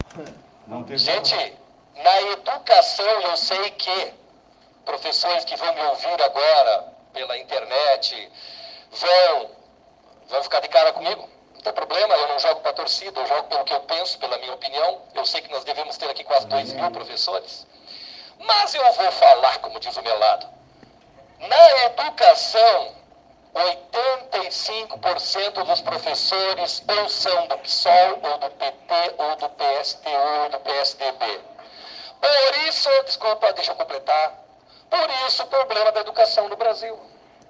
A fala foi proferida durante a sessão dessa segunda-feira (04):
Fala-vereador-Danubio-barcellos.ogg